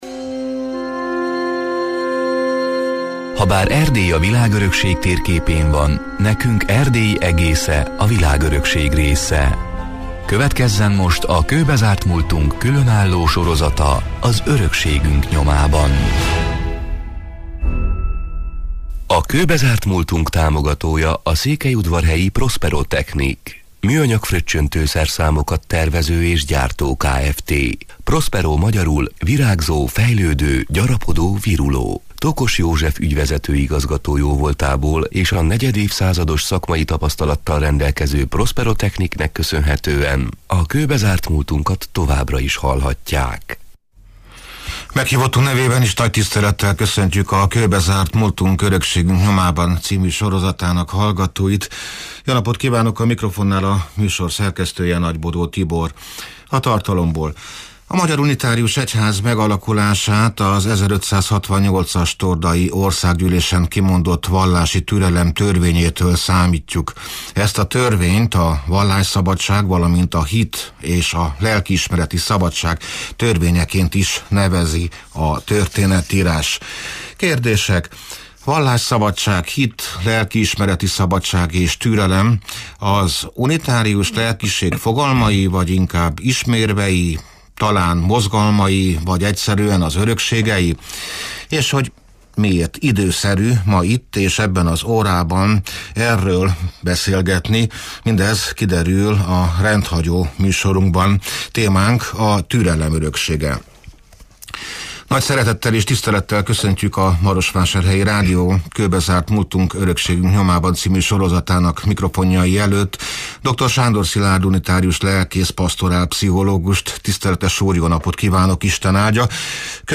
(elhangzott: 2024. szeptember 7-én, szombaton délben egy órától élőben)